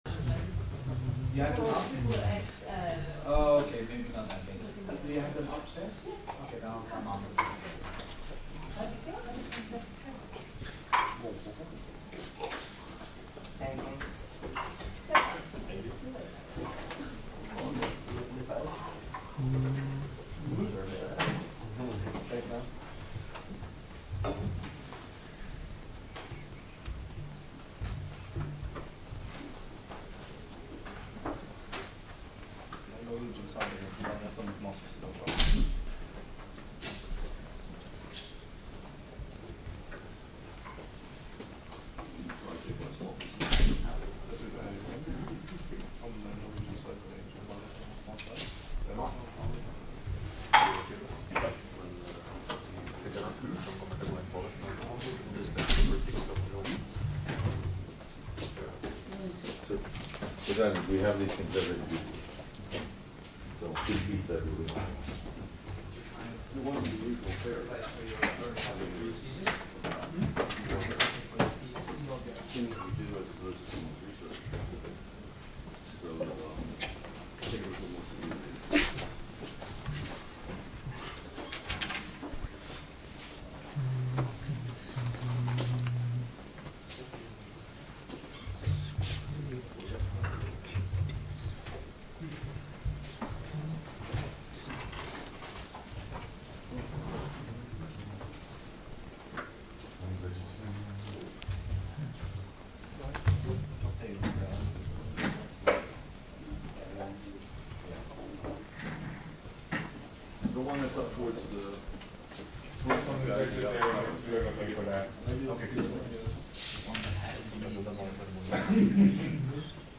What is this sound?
Emne: NISseminar